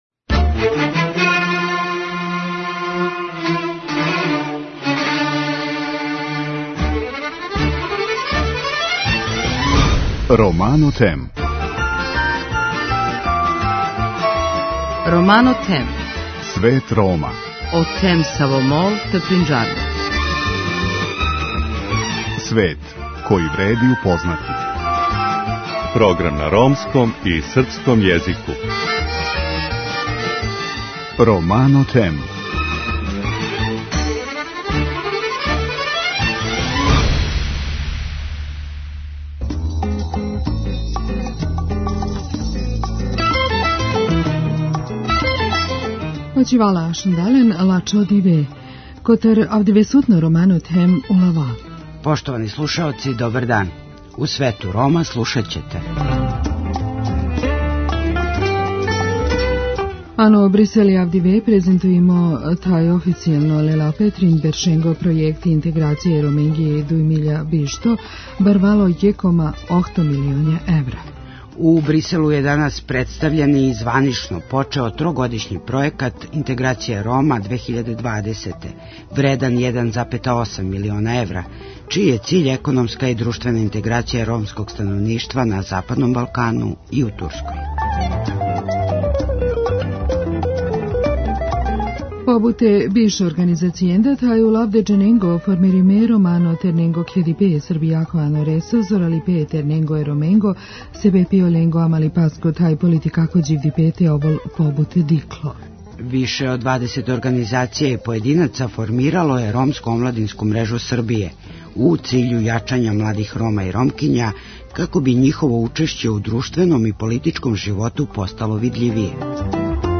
У интерјуу, који је дао специјално за нашу емисију, сазнајемо да изузетно цени ромску културу као и то да ће Норвешка наставити да подржава програме инклузије Рома, посебно на Балкану.